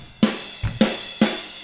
146ROCK F2-L.wav